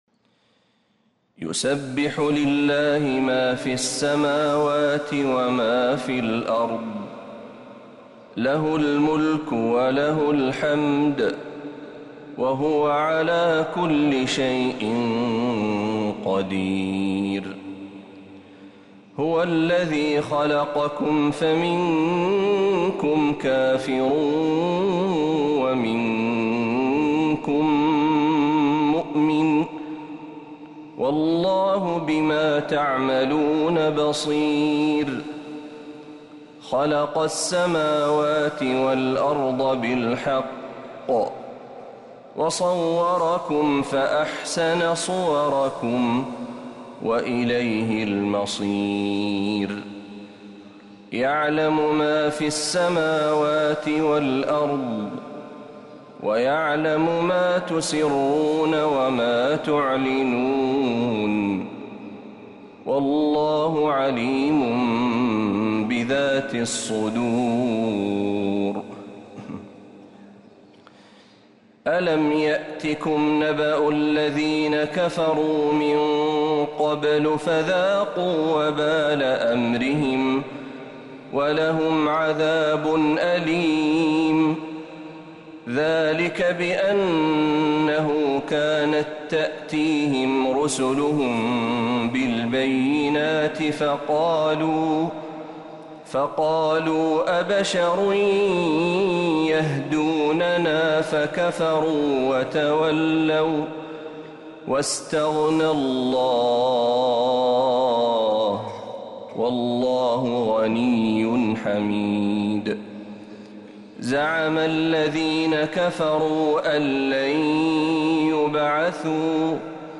سورة التغابن كاملة من الحرم النبوي > السور المكتملة للشيخ محمد برهجي من الحرم النبوي 🕌 > السور المكتملة 🕌 > المزيد - تلاوات الحرمين